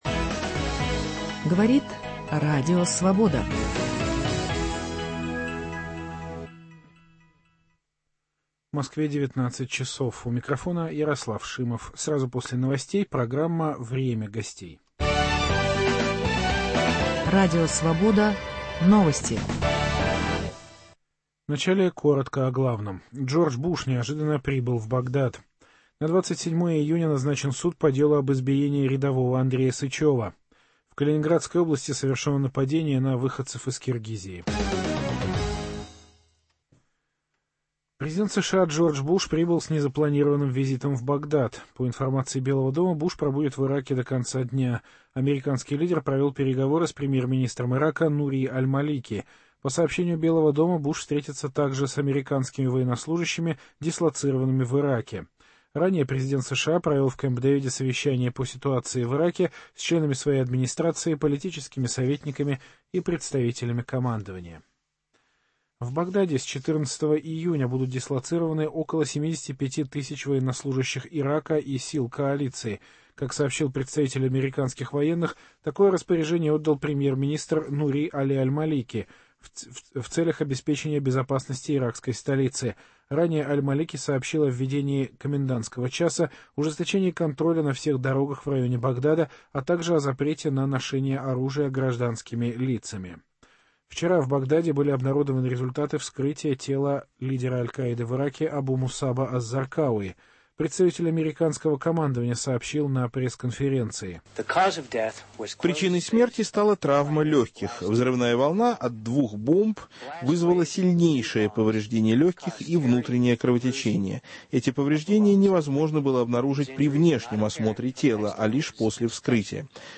Согласен ли с ним Григорий Явлинский? Лидер партии «Яблоко» - в нашей студии.